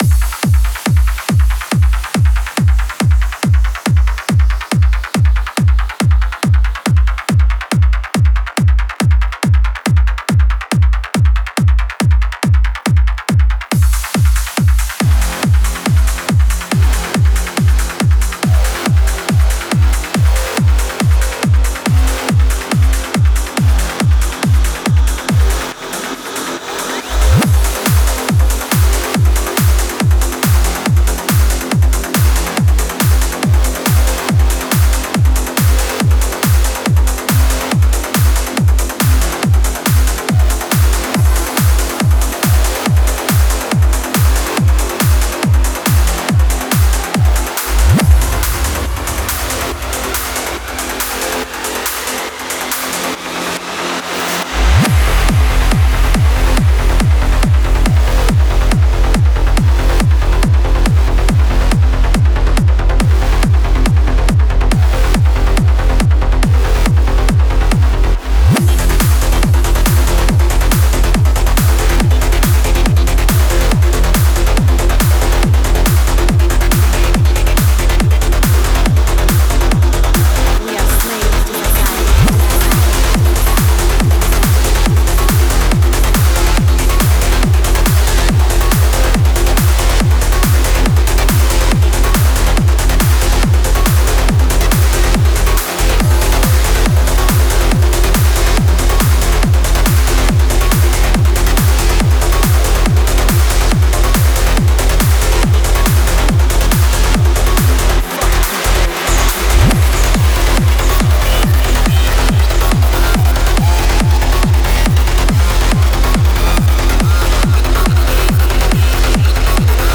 Стиль: Trance / Tech Trance